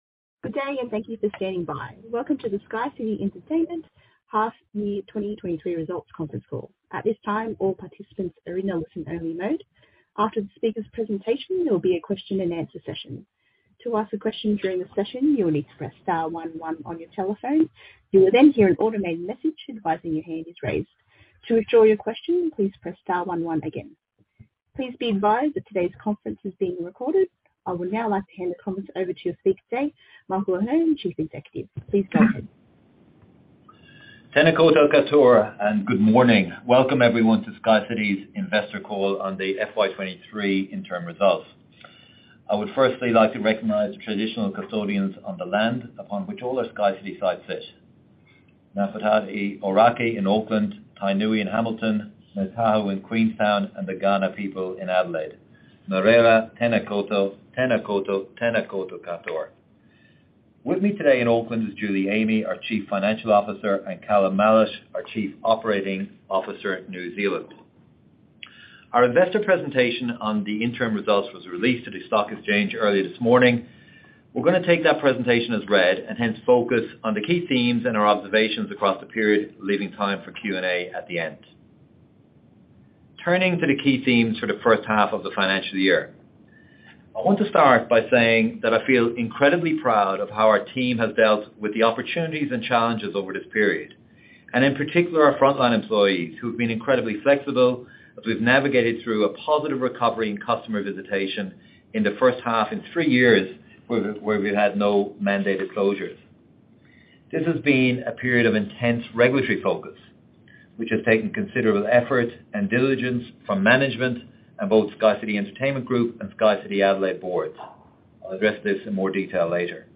2023-interim-results-conference-call-replay.mp3